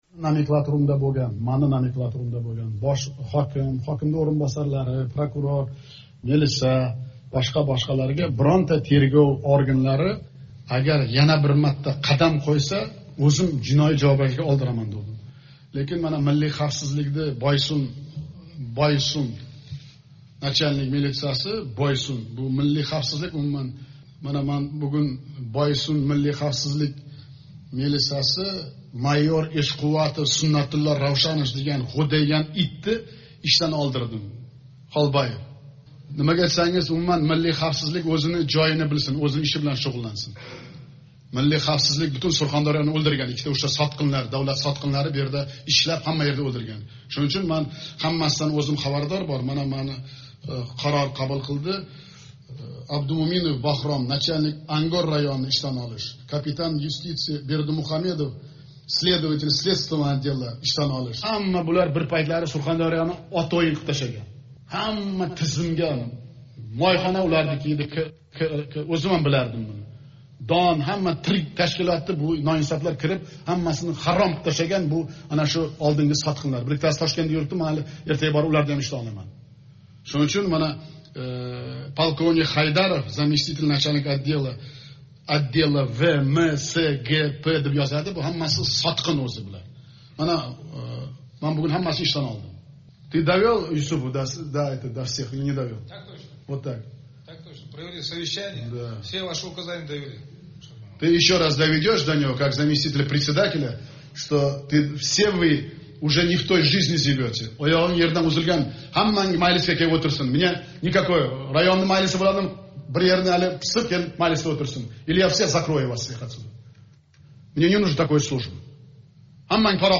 Озодликда эълон қилинаëтган бу аудиоëзувда Шавкат Мирзиëев Миллий хавфсизлик хизматининг йиллар давомидаги фаолиятини Сурхондарë вилоятини мисол қилиб ниҳоятда қаттиқ ғазаб билан гапиради:
Так точно деган жавобни эшитгандан кейин Мирзиëев гапини яна кучлироқ оҳангда давом эттиради: